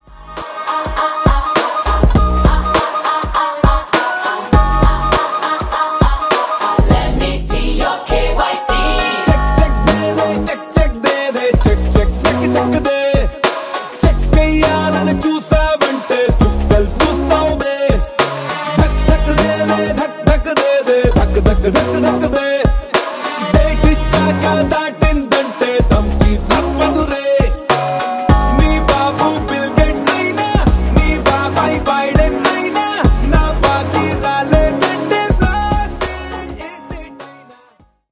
Поп Музыка # Танцевальные # Индийские
весёлые